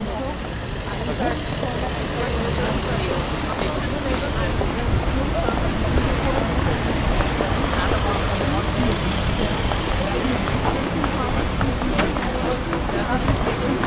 路径, 人群, 路径
描述：strasse menschen verkehr
Tag: verkehr menschen 大街